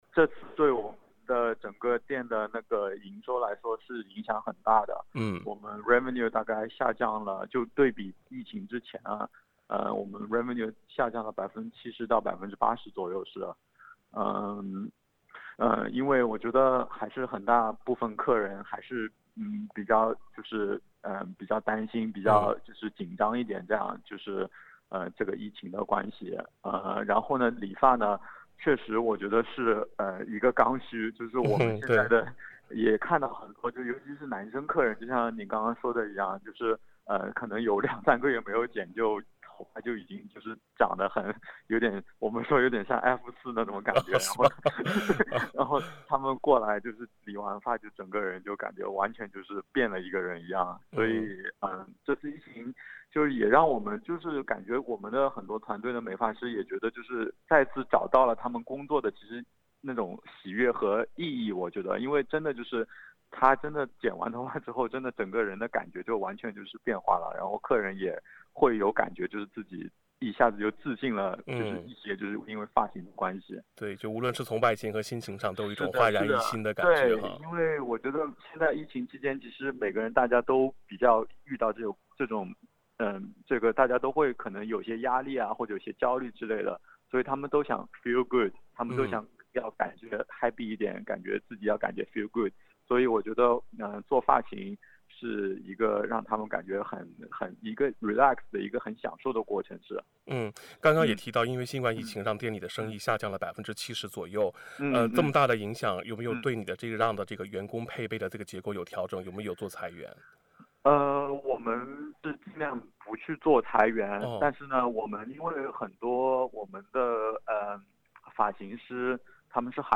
（點擊上方圖片，收聽寀訪錄音 ）